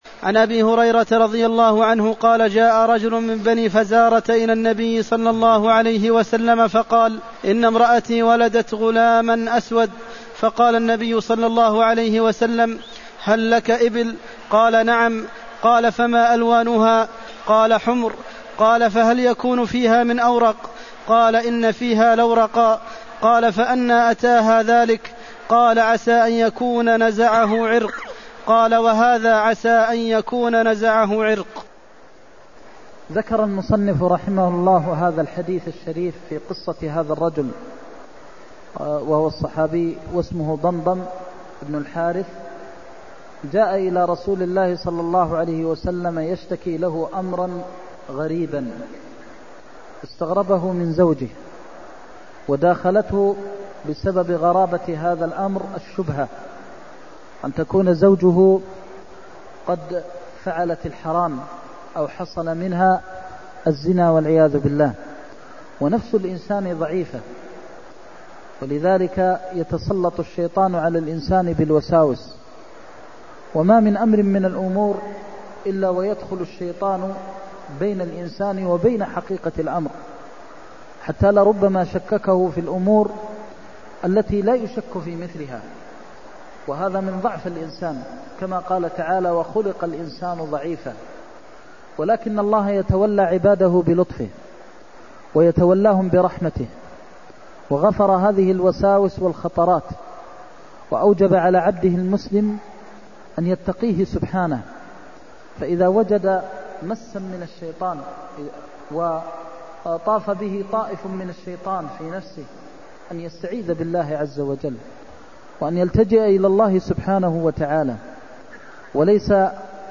المكان: المسجد النبوي الشيخ: فضيلة الشيخ د. محمد بن محمد المختار فضيلة الشيخ د. محمد بن محمد المختار هل فيها من أورق (308)